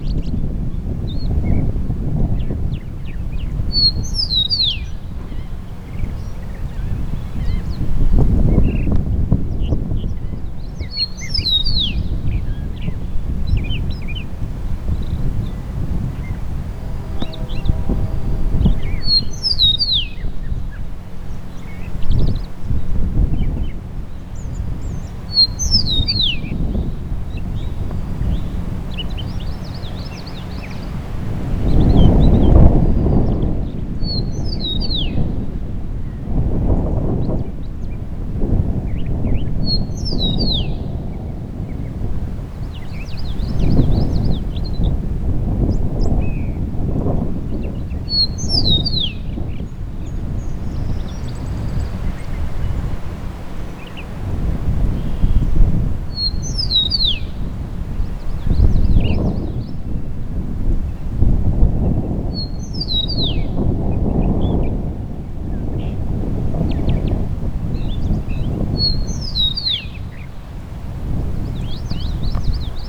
Speaking of spring and nest-building, here’s a 4/11 recording of a male Eastern Meadowlark on territory at Money Point in Chesapeake. It was singing its heart out near the top of a tree overlooking an un-mowed field there (suitable habitat). My mic also picked up the wind (of course), as well as a Common Yellowthroat, a Laughing Gull, and a Northern Mockingbird.